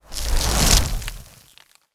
FrostFadeIn.wav